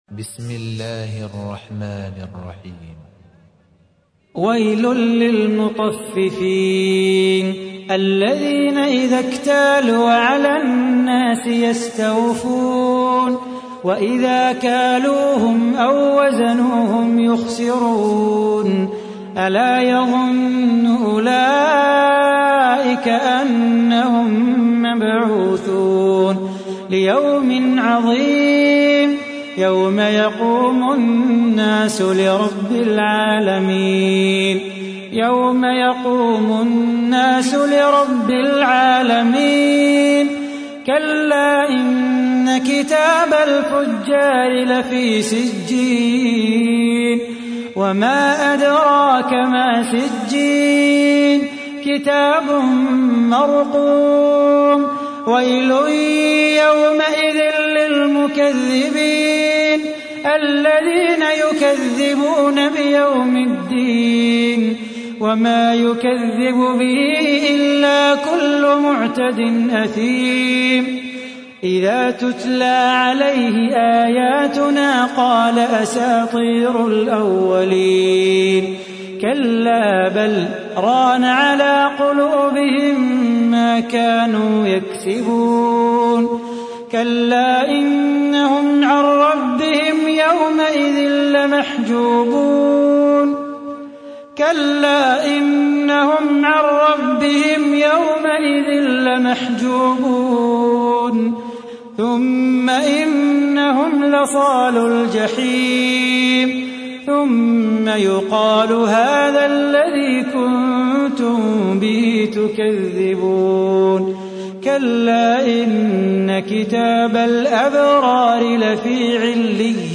تحميل : 83. سورة المطففين / القارئ صلاح بو خاطر / القرآن الكريم / موقع يا حسين